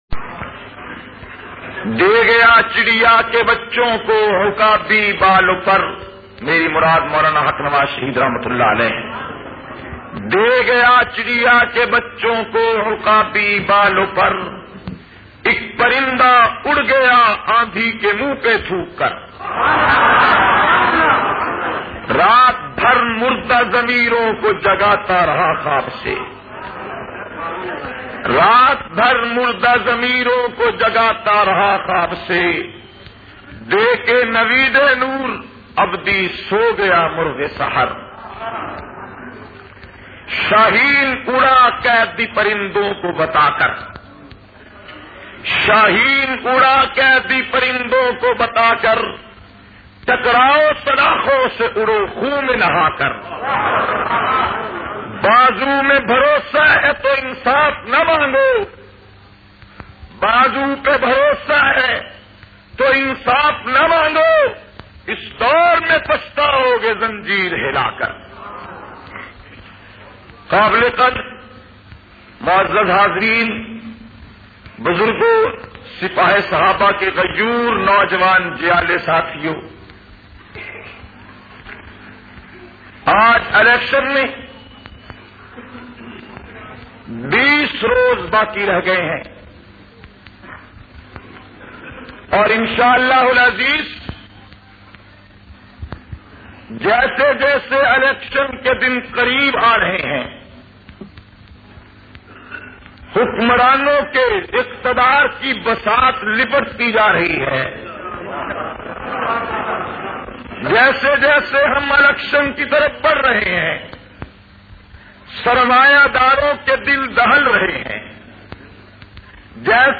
35- Election-bayan-assambly-say-khitab.mp3